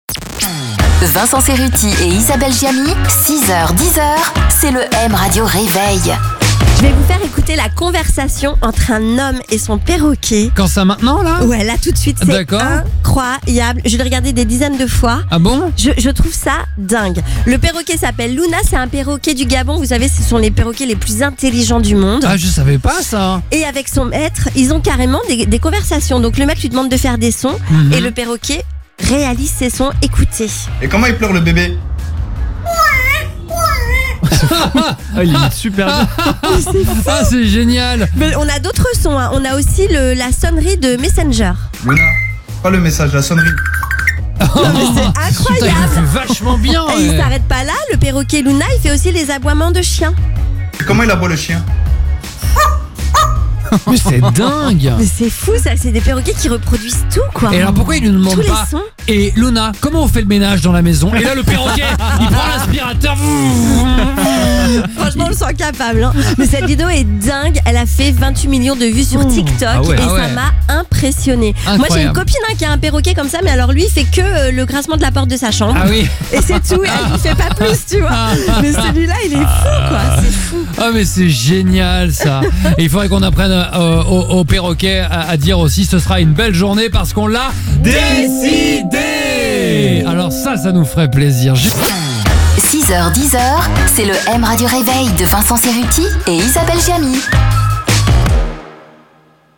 On vous fait écouter la conversation entre un homme et son perroquet, c’est incroyable !